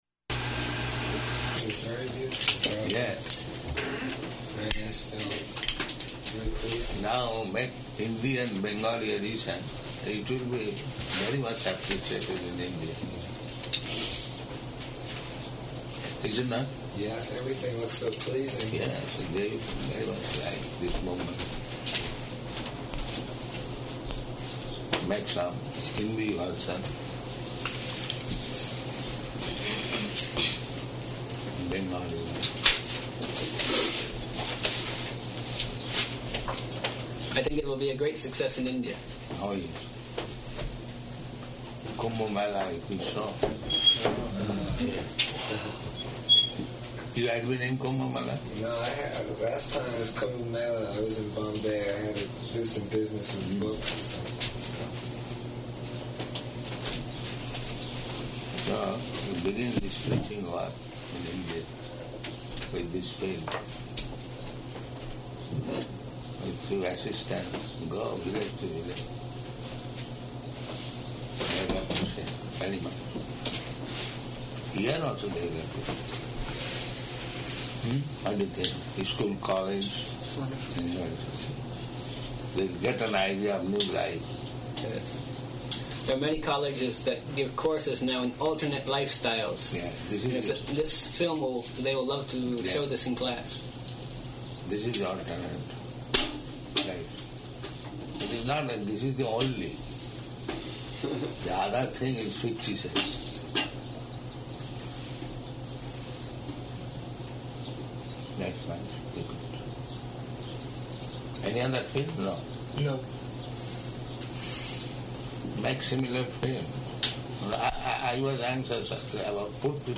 Room Conversation After Film
-- Type: Conversation Dated: June 28th 1976 Location: New Vrindavan Audio file